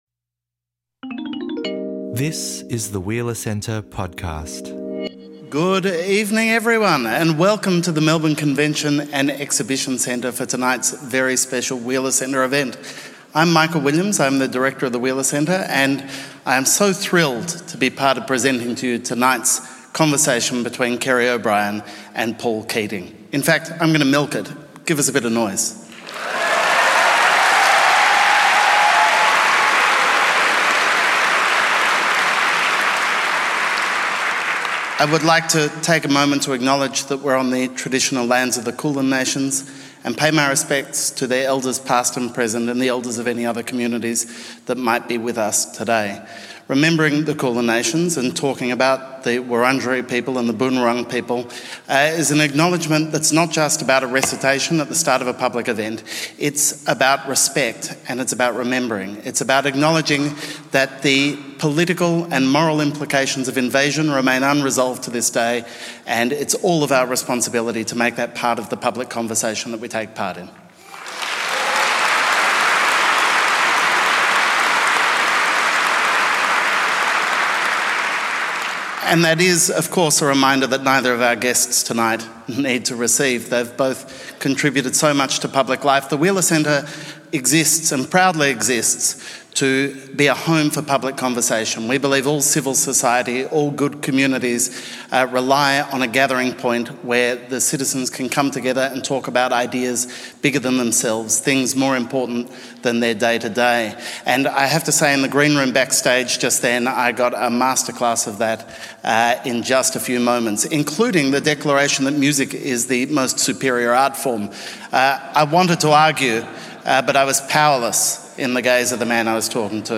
The pair join us for an extended discussion of the life and legacy of one of Australia’s most fascinating political figures.